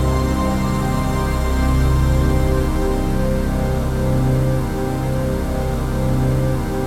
CHRDPAD051-LR.wav